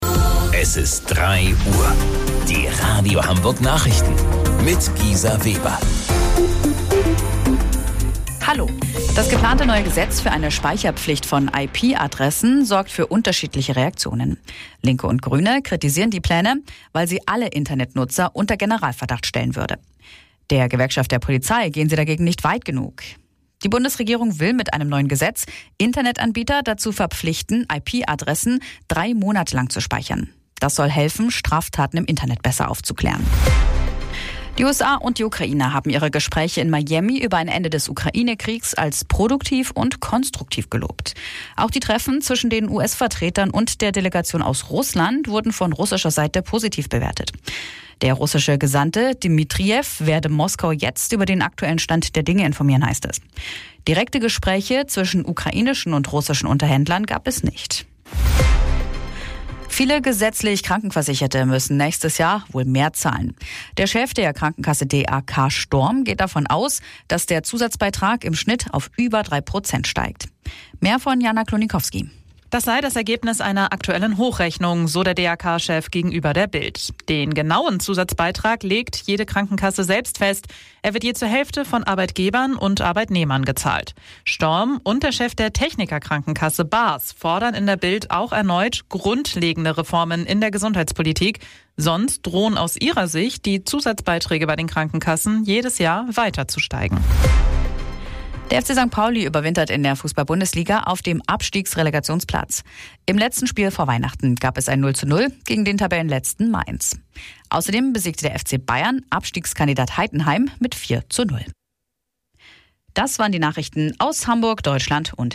Radio Hamburg Nachrichten vom 22.12.2025 um 03 Uhr